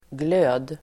Uttal: [glö:d]